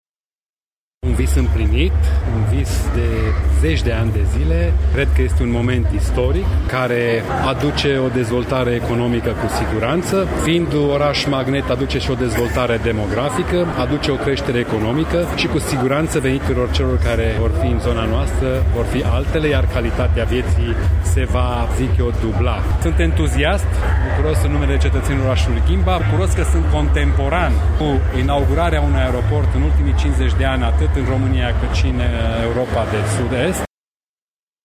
Deschiderea aeroportului este, pentru brașoveni, un vis împlinit, iar pentru ghimbășeni presupune o dezvoltare economică importantă, spune primarul orașului Ghimbav, Ionel Fliundra: